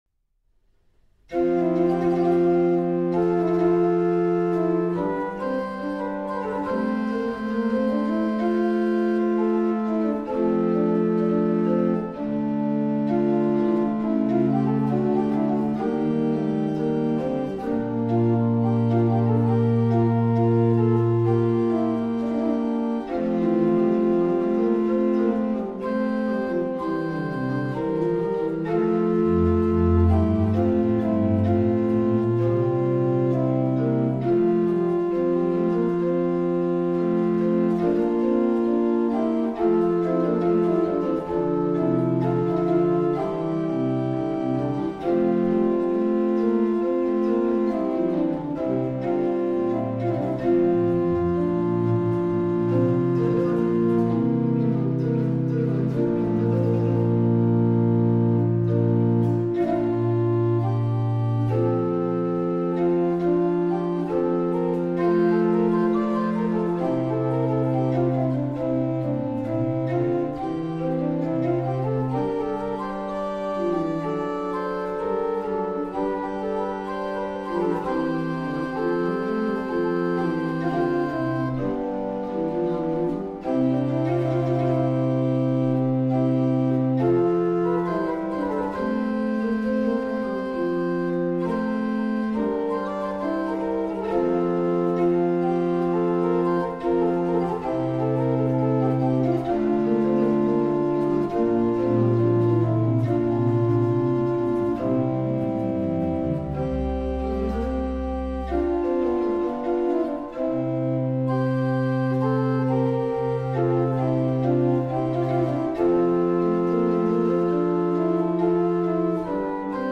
Organist